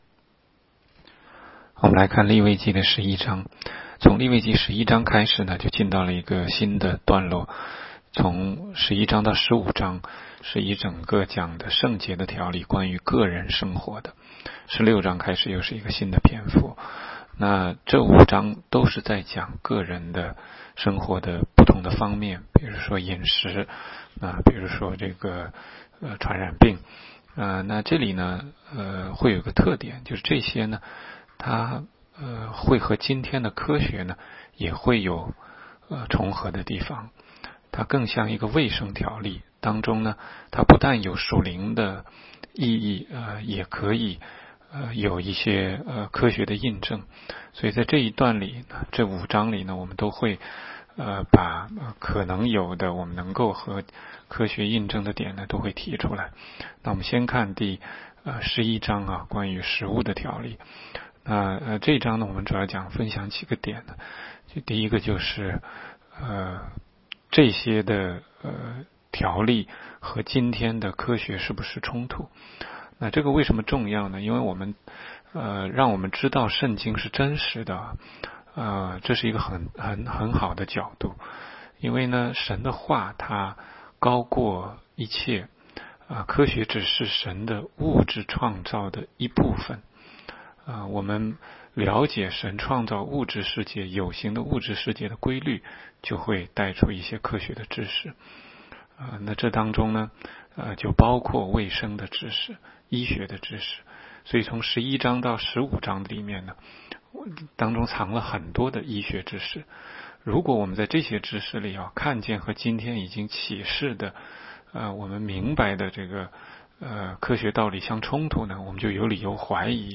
16街讲道录音 - 每日读经-《利未记》11章